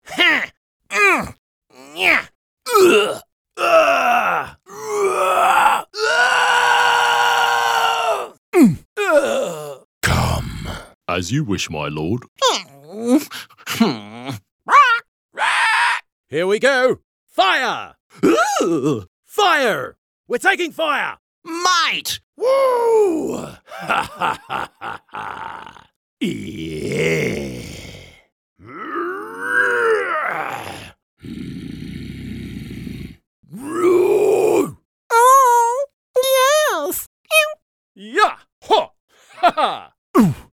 Male
English (Australian)
Adult (30-50)
Video Games
Male Voice Over Talent